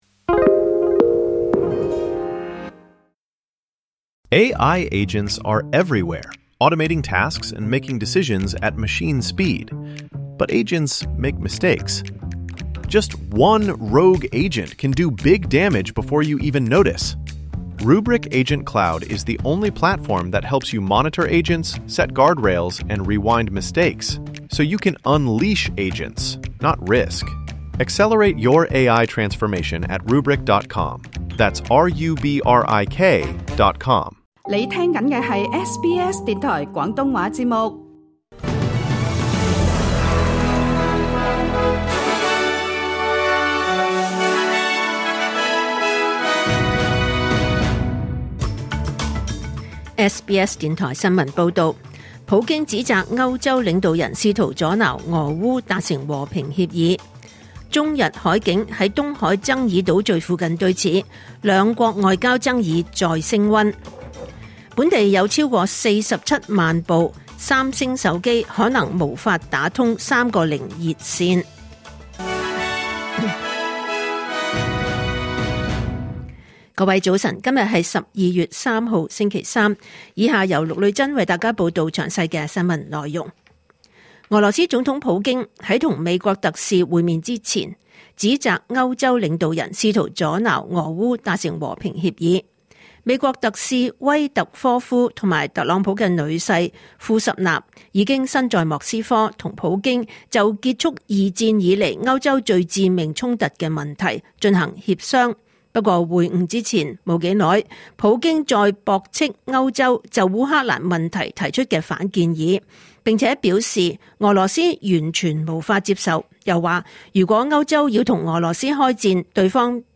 2025年12月3日SBS廣東話節目九點半新聞報道。